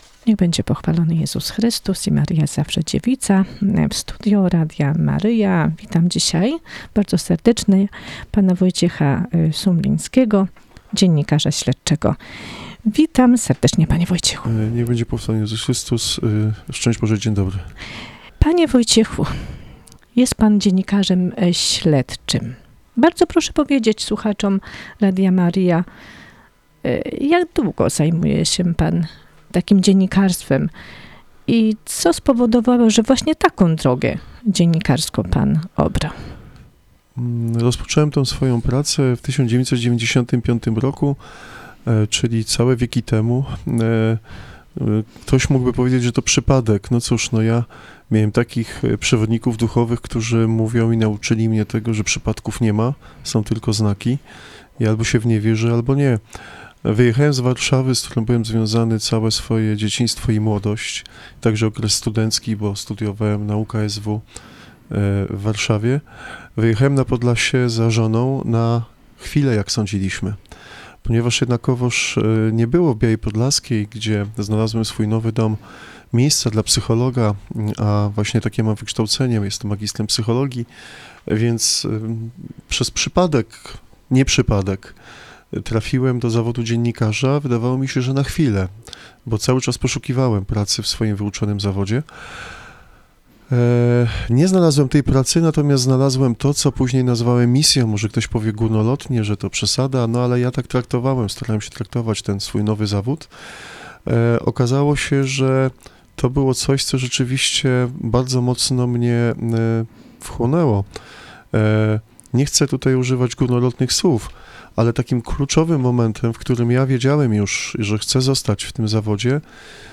Wywiady-radiowe
Wywiady radiowe z gośćmi Radia Maryja w Chicago